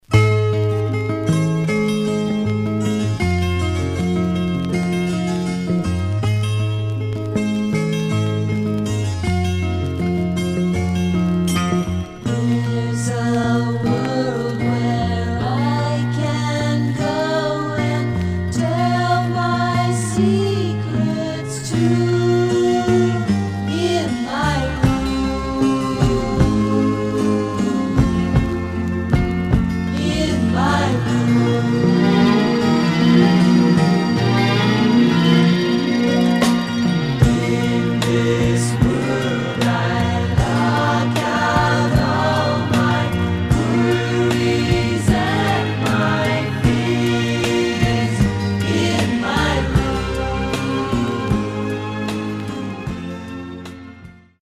Mono
Garage, 60's Punk